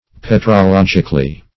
Petrologically \Pet`ro*log"ic*al*ly\, adv.